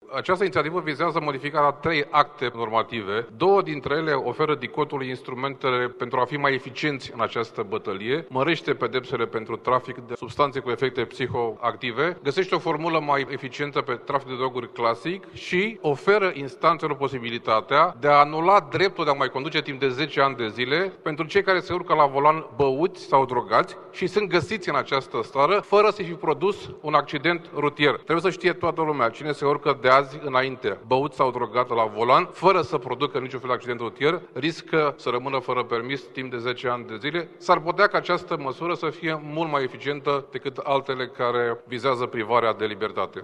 Senatorul PSD Robert Cazanciuc, unul dintre iniţiatori: